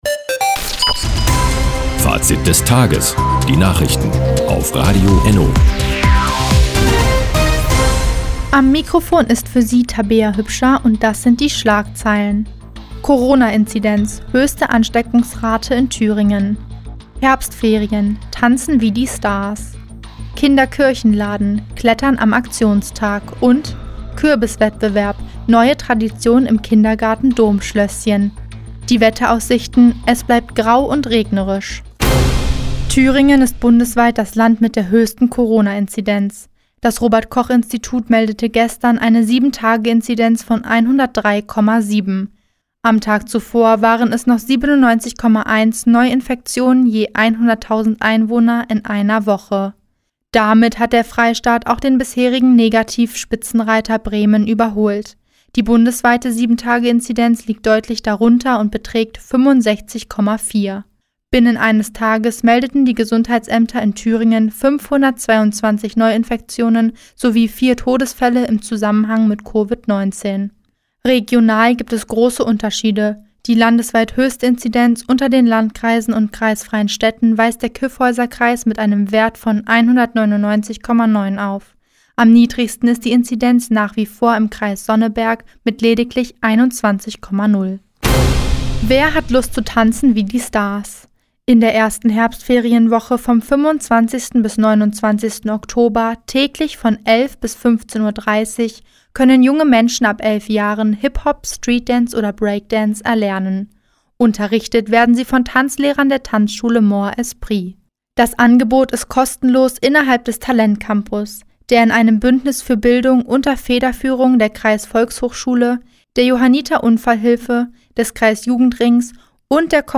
Do, 16:03 Uhr 14.10.2021 Neues von Radio ENNO Fazit des Tages Seit Jahren kooperieren die Nordthüringer Online-Zeitungen und das Nordhäuser Bürgerradio ENNO. Die tägliche Nachrichtensendung ist jetzt hier zu hören...